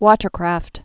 (wôtər-krăft, wŏtər-)